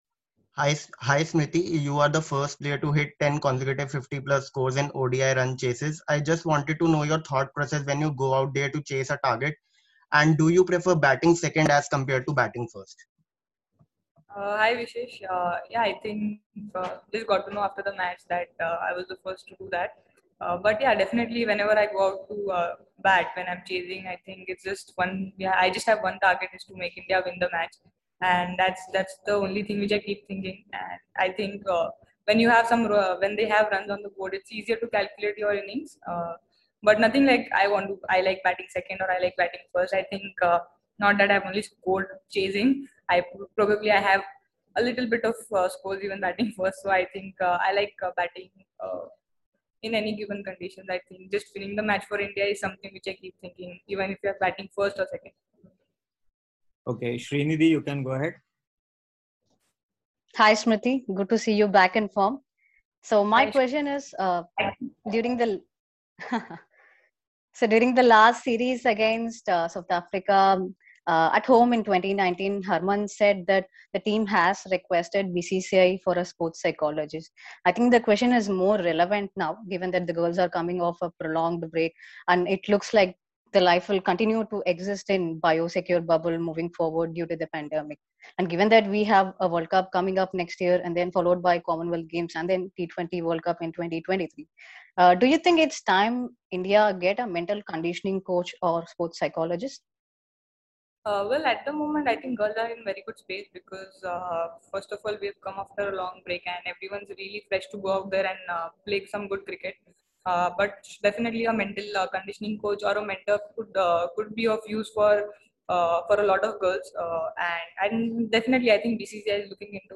Smriti Mandhana, Member, Indian Women’s ODI Team addressed a virtual press conference before the 3rd ODI against South Africa at Lucknow.